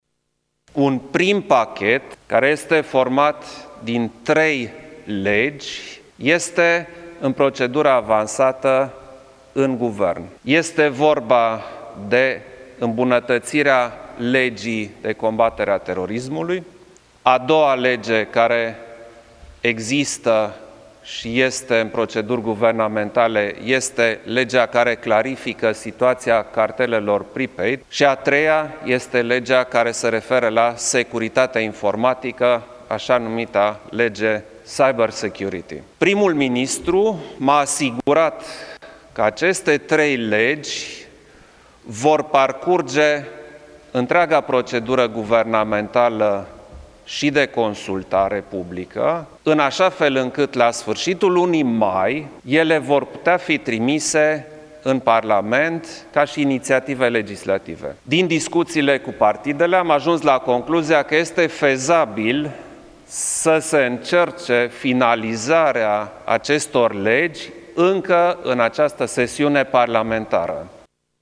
Președintele Klaus Iohannis a spus, după consultările cu partidele, că legile securității sunt învechite și inadaptate si că vor fi două pachete de legi noi în acest sector, primul în fază avansată și un al doilea pentru care președintele a propus o comisie în Parlament.